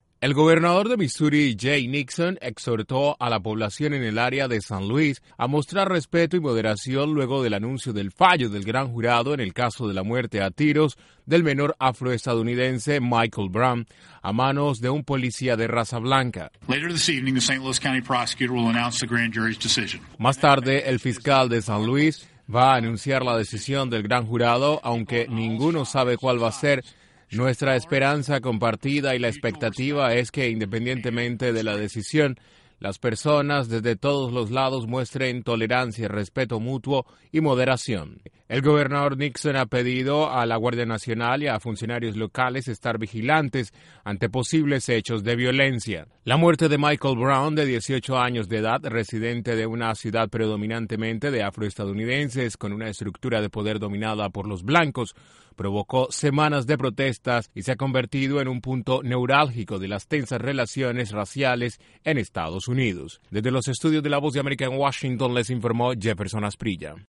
El Gobernador del estado de Missouri llamó a la población a mantener la calma ante la decisión del gran jurado en el caso del asesinato del joven afro estadounidense Michael Brown. Desde la Voz de América en Washington informa